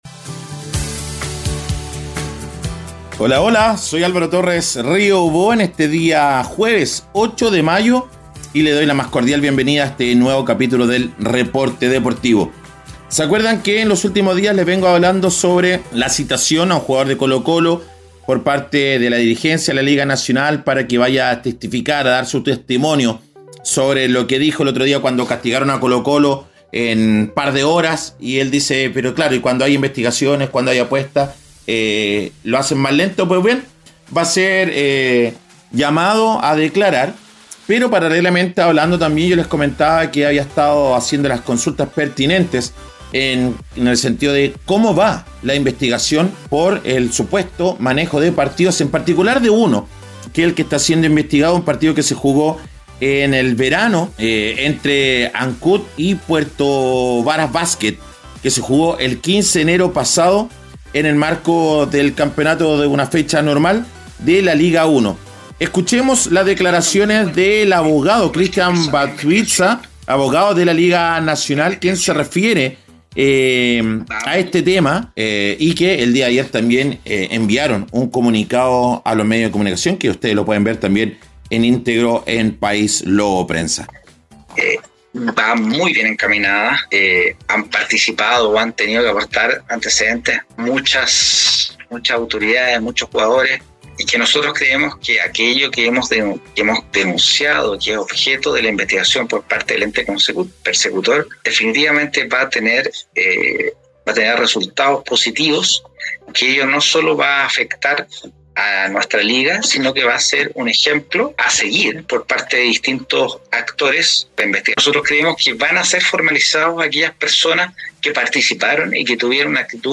En las últimas 24 horas, ha recopilado información clave para presentarte un completo y dinámico Reporte Deportivo.